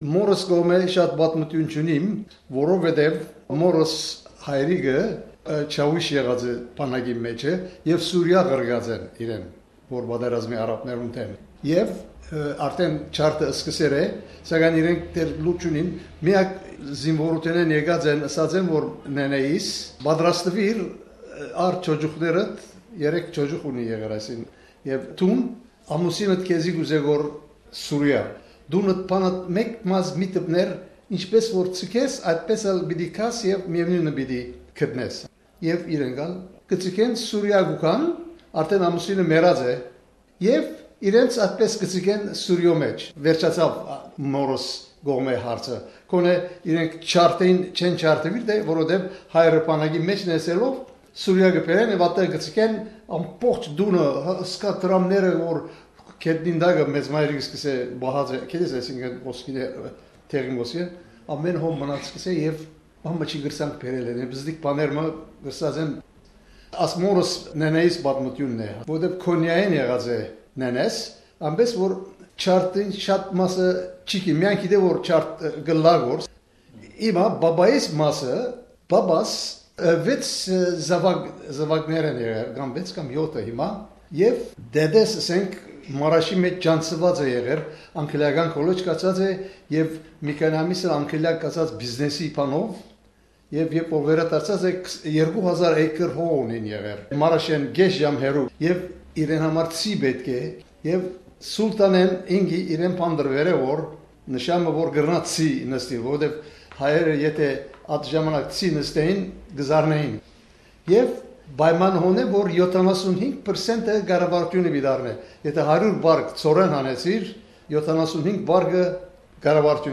The mass killing of Armenians by Ottoman Turks during World War I is an important part of the Armenian-Australian identity. While the Turkish government has resisted widespread calls for it to recognise the 1915-16 killings as genocide and historians argue the details, in this interview series, second generation Armenian-Australians share their own memories growing up with parents who survived.